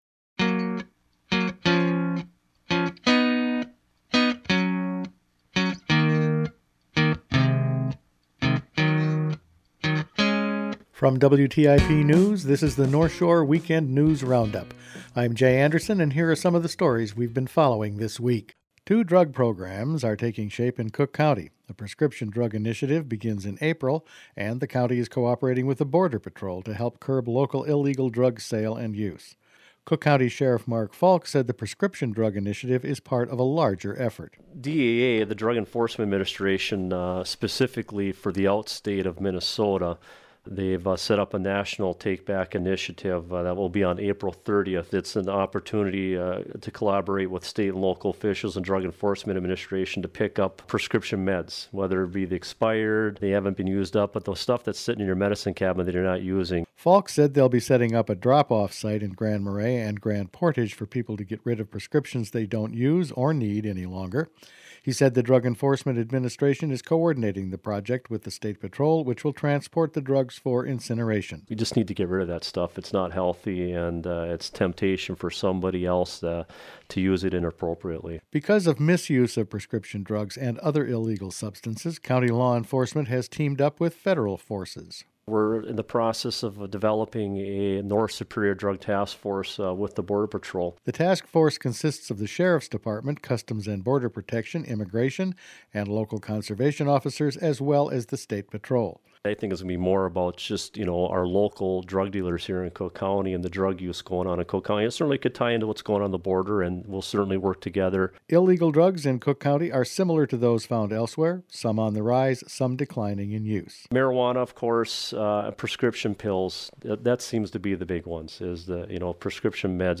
Each weekend WTIP news produces a round up of the news stories it's been following this week. County drug programs, a new golf course manager and a state health study that leaves out Cook County were all in this week's news.